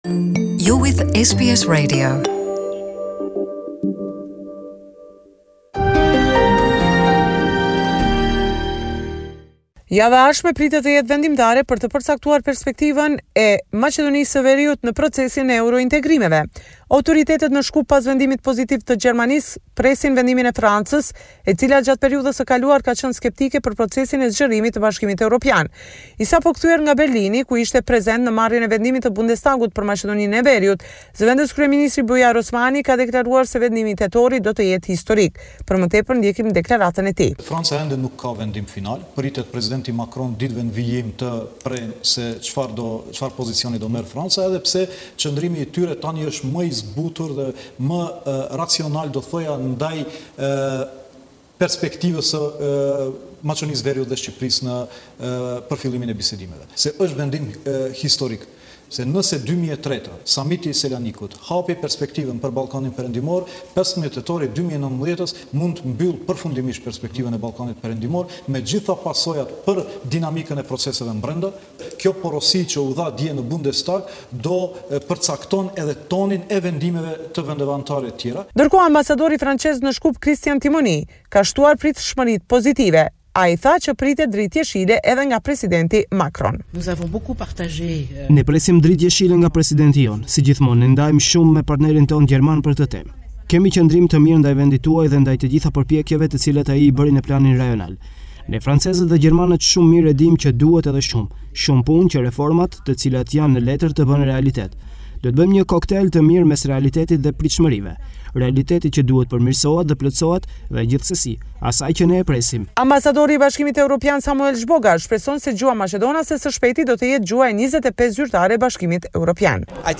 This is a report summarising the latest developments in news and current affairs in North Macedonia.